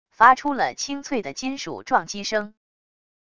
发出了清脆的金属撞击声wav音频